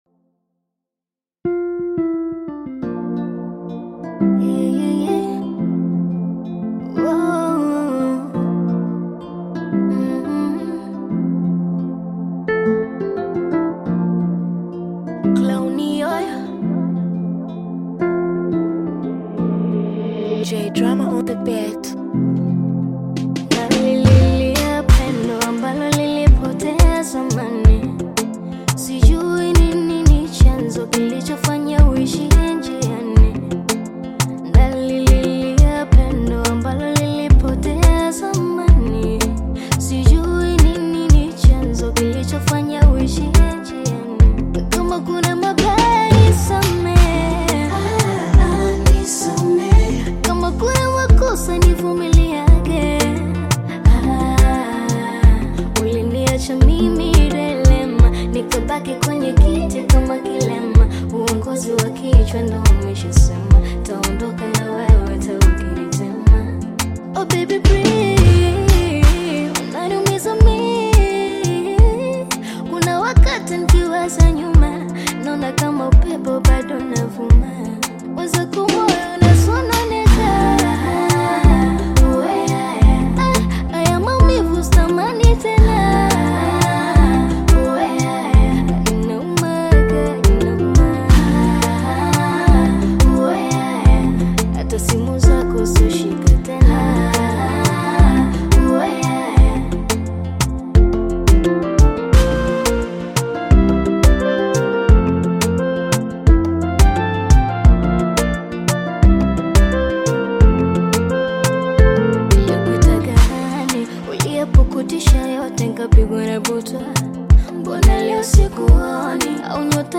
Tanzanian upcoming Bongo Flava artist, singer and songwriter
Bongo Flava